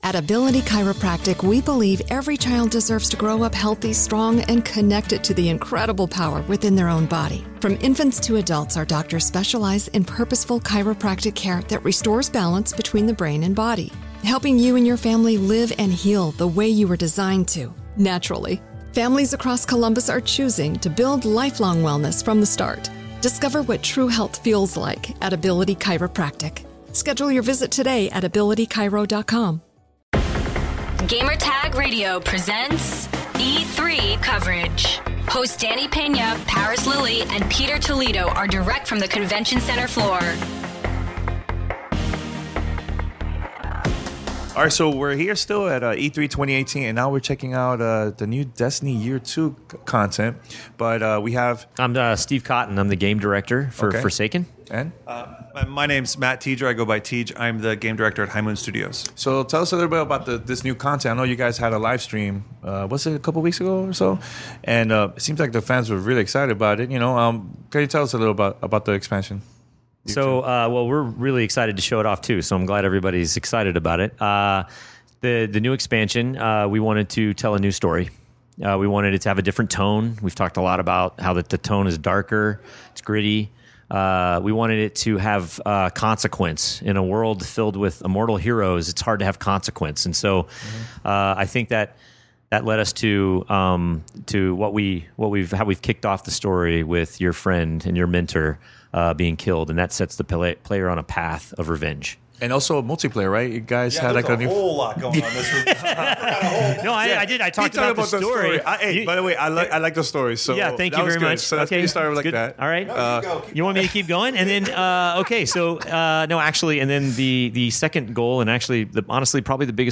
E3 2018: Destiny 2: Forsaken Interview